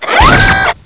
laser.wav